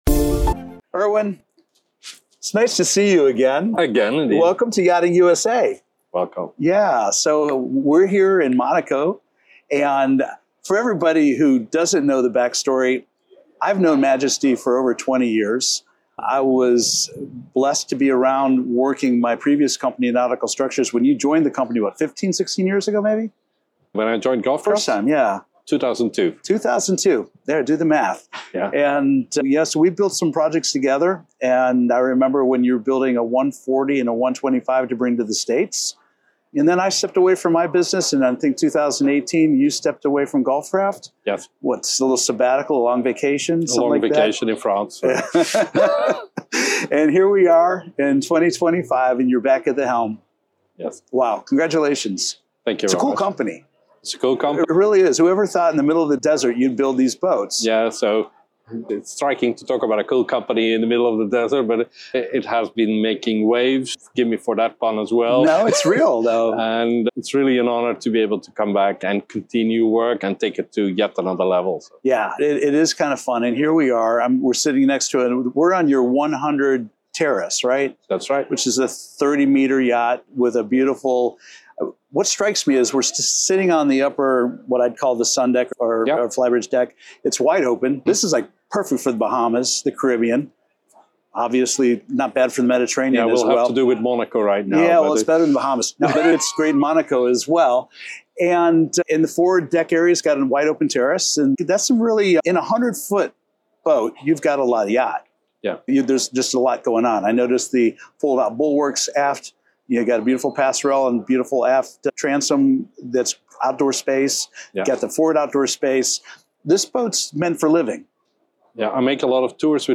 Monaco Yacht Show
Watch more exclusive interviews from the Monaco Yacht Show on Yachting International Radio — the voice of the global superyacht community.